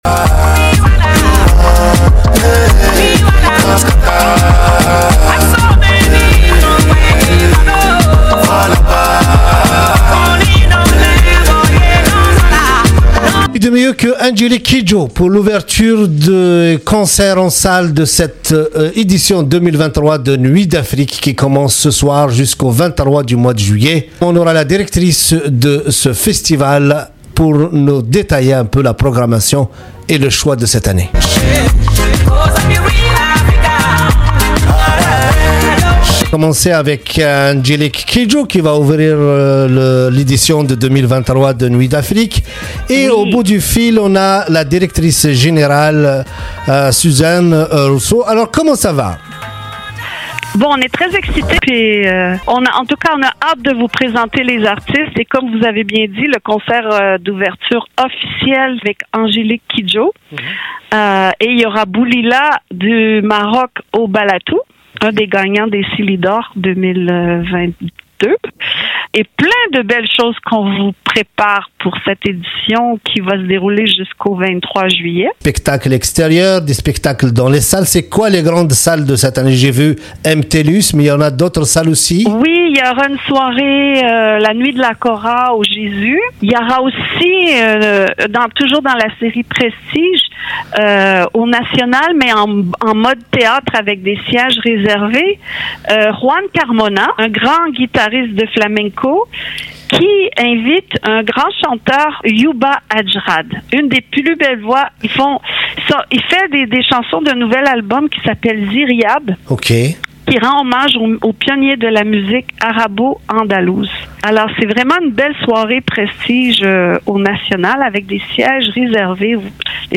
L’entrevue